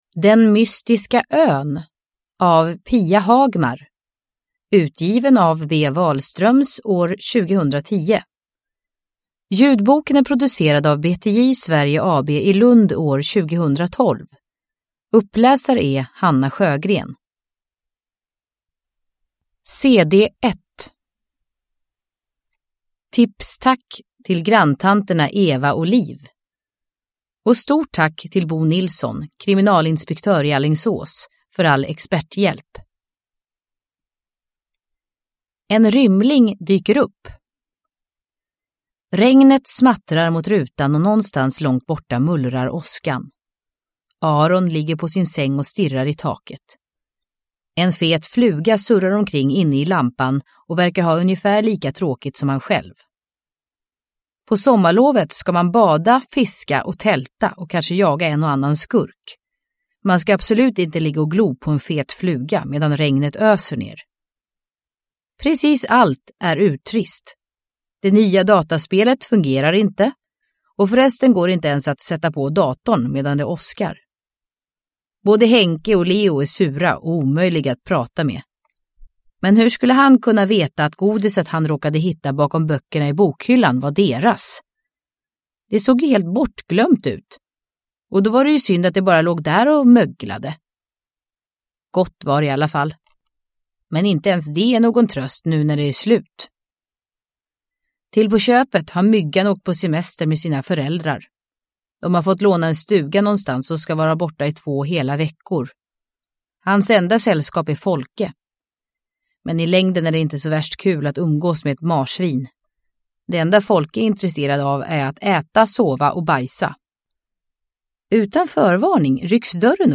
Den mystiska ön – Ljudbok – Laddas ner